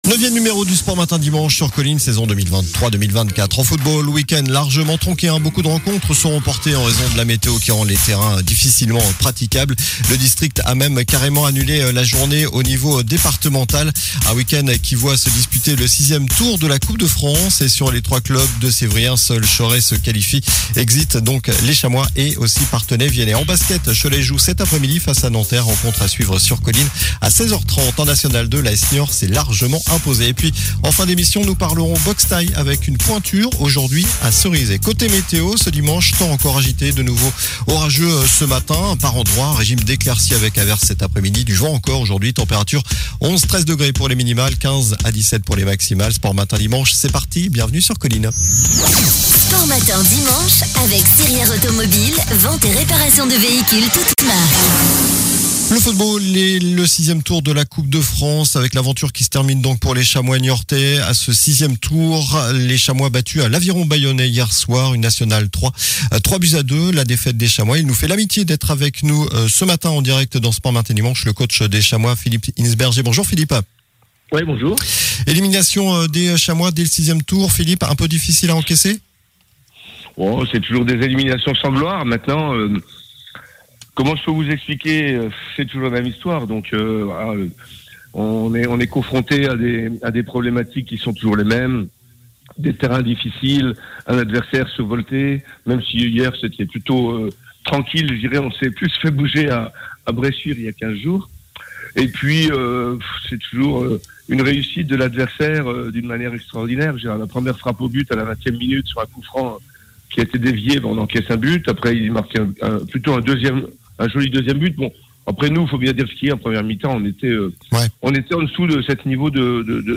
sport infos